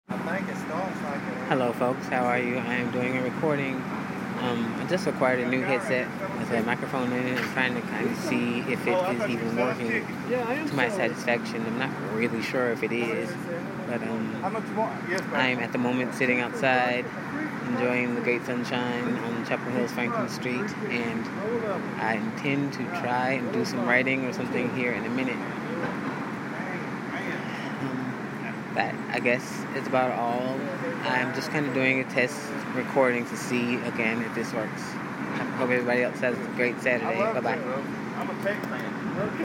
new mike test